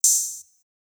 Juicy Open Hat.wav